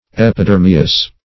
Epidermeous \Ep`i*der"me*ous\